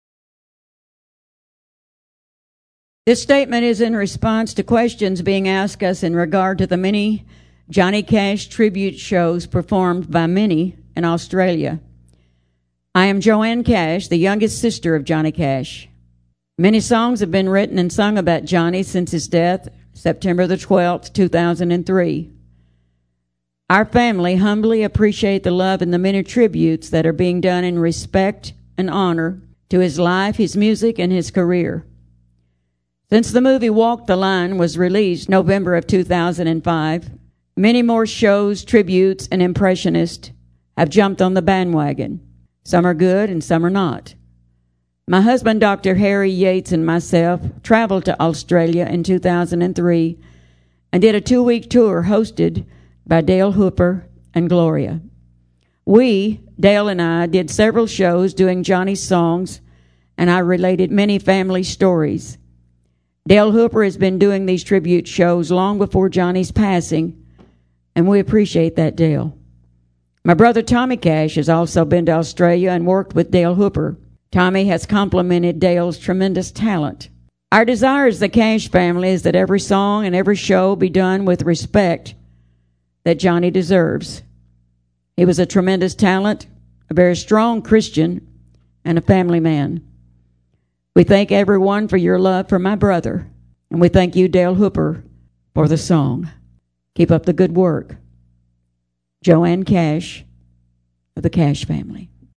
Media Statement